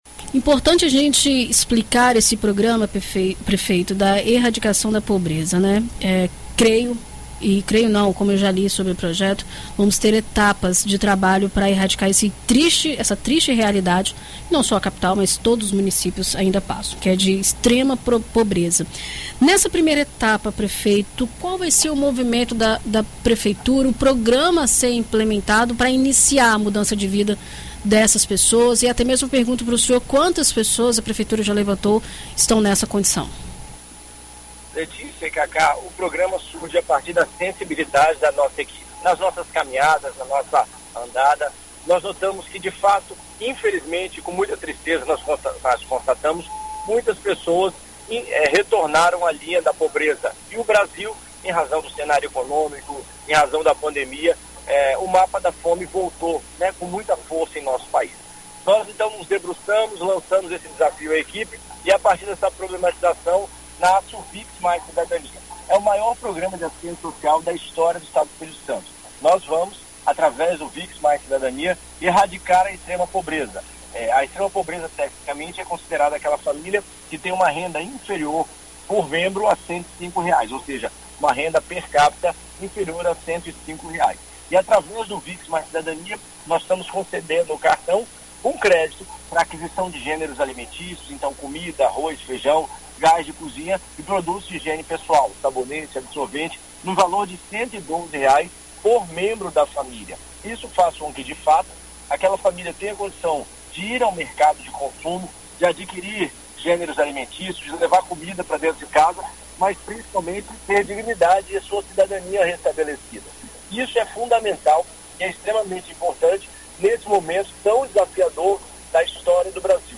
O investimento para o programa é de quase R$13,7 milhões por ano e vai disponibilizar todos os meses R$112,88 (o equivalente a 8,67% do salário mínimo) para cada membro de famílias da capital dentro dos critérios estabelecidos. Em entrevista à BandNews FM Espírito Santo, nesta quinta-feira (16), o prefeito de Vitória, Lorenzo Pazolini, fala sobre o projeto que visa erradicar a extrema pobreza no município.